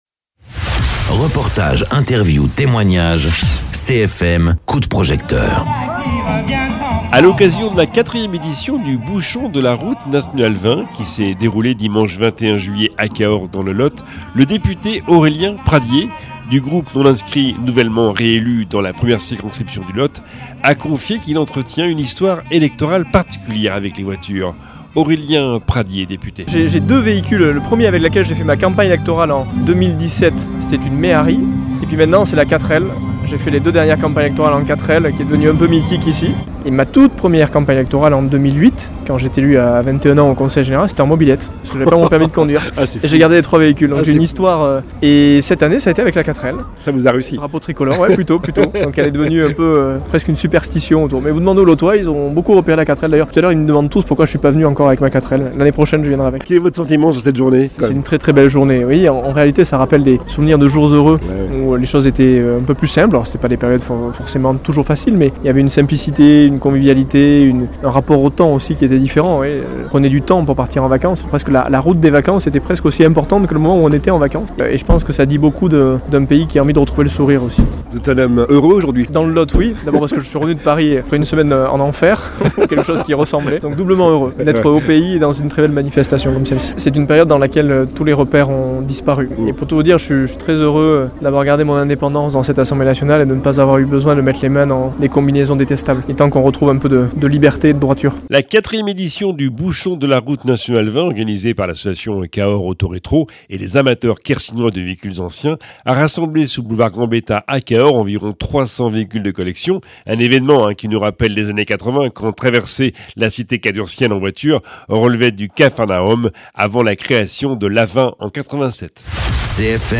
Interviews
A l’occasion de la 4e édition du Bouchon de la RN 20 qui s’est déroulée dimanche 21 juillet à Cahors dans le Lot, le député lotois Aurélien Pradié confie entretenir une histoire électorale particulière avec les voitures...